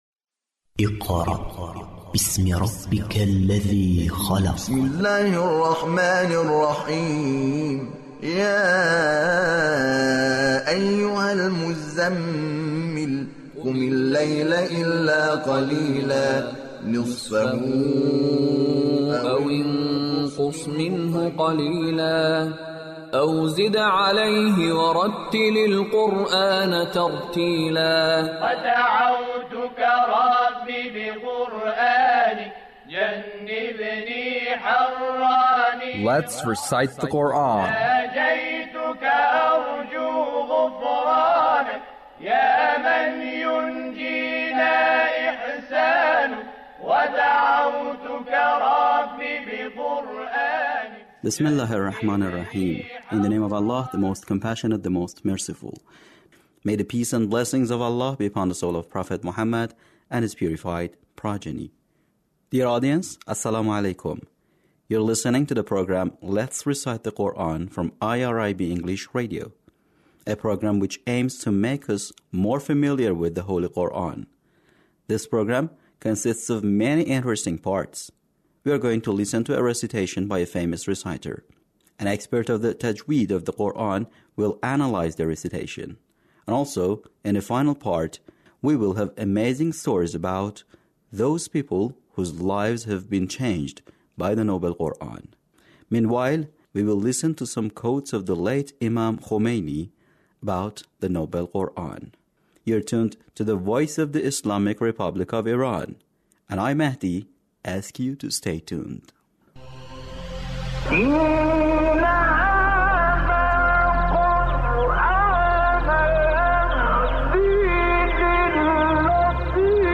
Recitation of sura al-Ma'ida - Attractiveness of the Noble Qur'an